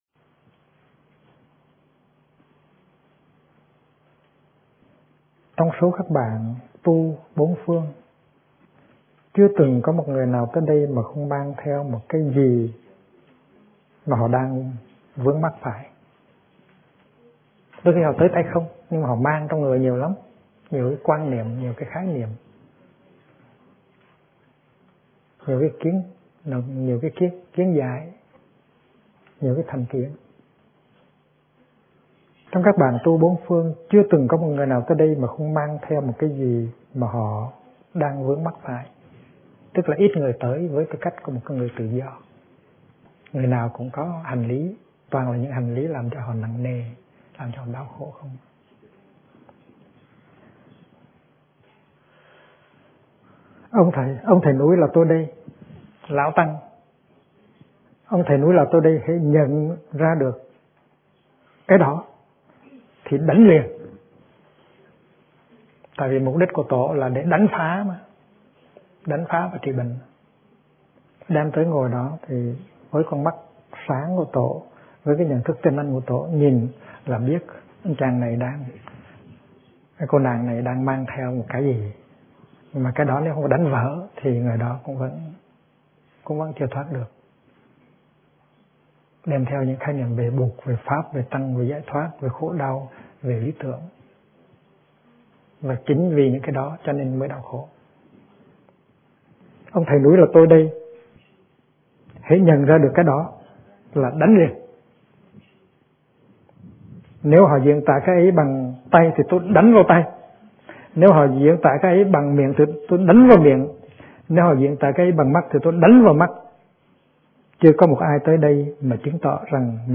Kinh Giảng Tri Kiến Chân Thật - Thích Nhất Hạnh